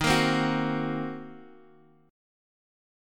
Ebm6 Chord (page 3)
Listen to Ebm6 strummed